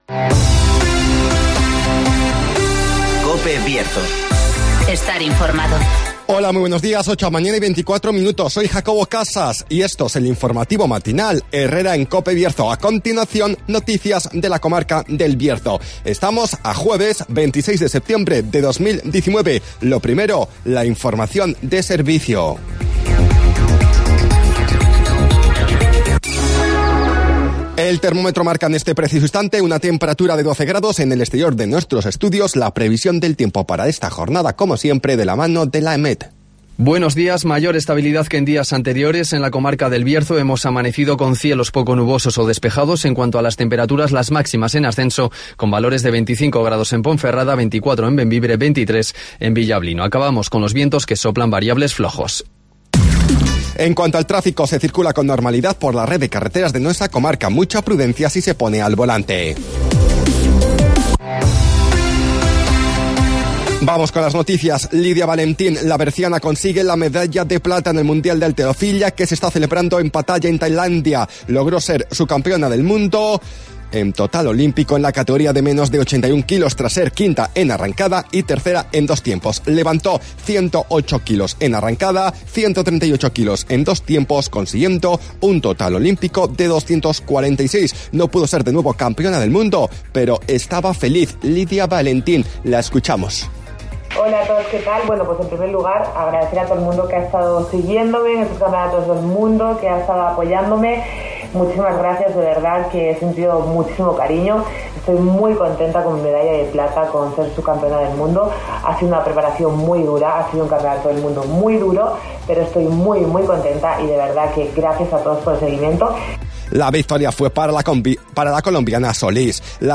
INFORMATIVOS BIERZO
-Conocemos las noticias de las últimas horas de nuestra comarca, con las voces de los protagonistas